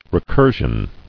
[re·cur·sion]